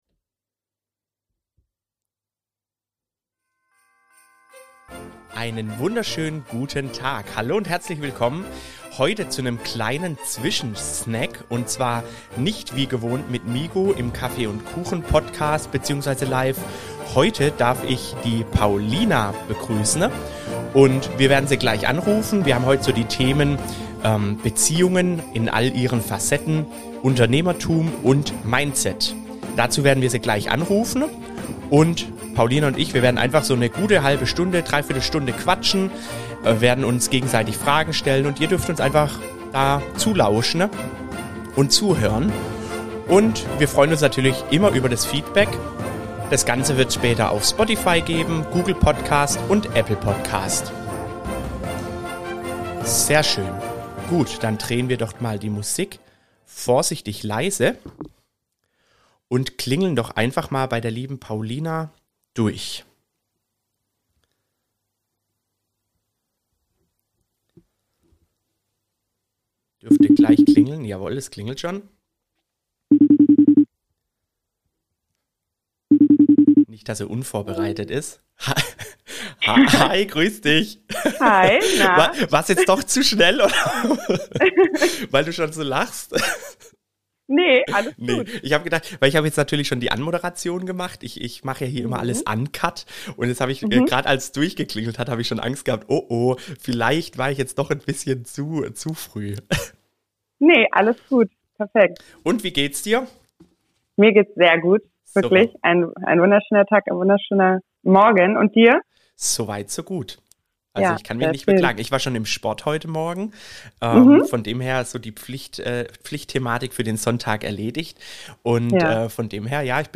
Ich hoffe ihr verzeiht mir und bleibt trotzdem dabei, da es eine echt super spannende Unterhaltung wurde!